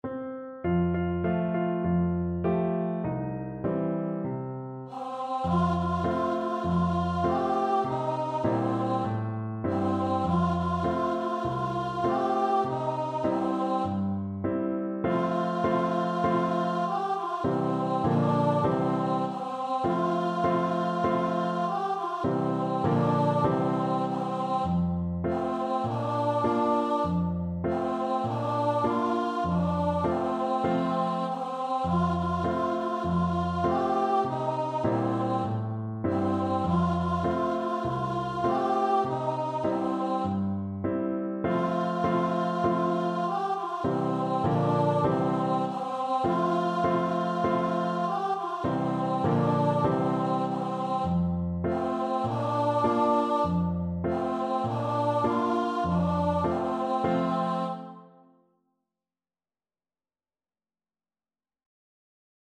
Voice
4/4 (View more 4/4 Music)
C5-G5
F major (Sounding Pitch) (View more F major Music for Voice )
Moderato
Zairian
kee_chee_VOICE.mp3